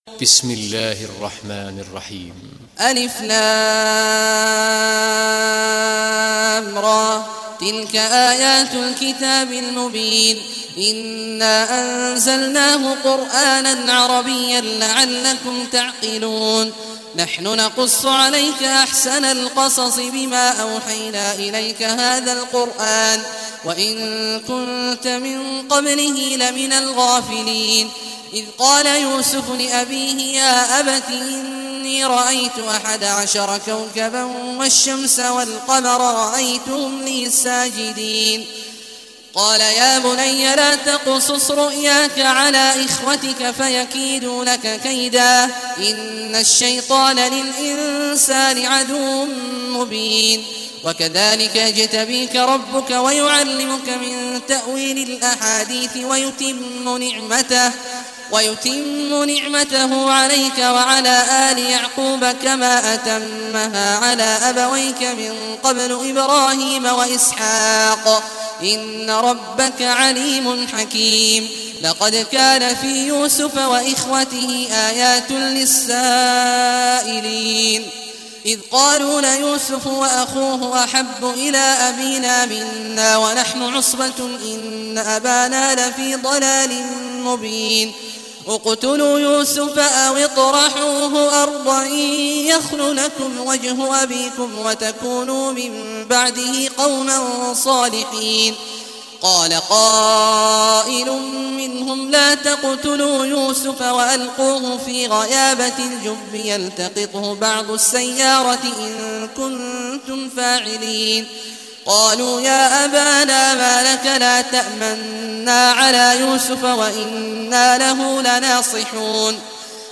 Surah Yusuf Recitation by Sheikh Abdullah Juhany
Surah Yusuf, listen or play online mp3 tilawat / recitation in Arabic in the beautiful voice of Sheikh Abdullah Awad al Juhany.